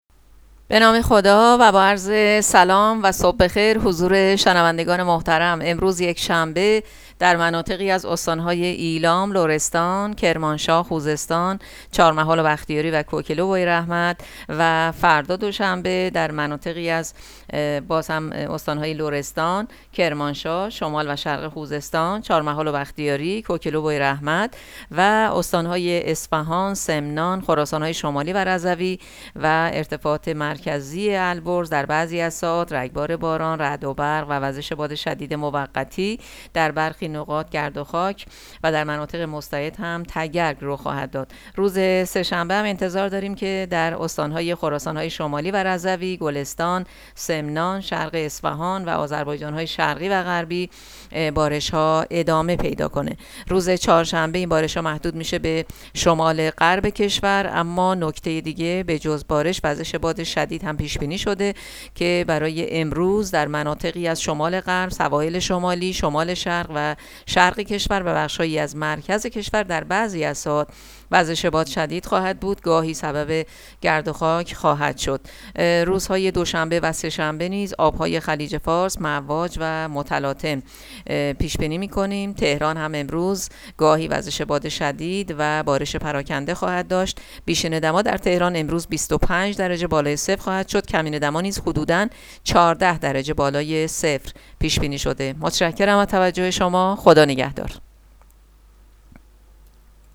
گزارش رادیو اینترنتی پایگاه‌ خبری از آخرین وضعیت آب‌وهوای ۱۷ فروردین؛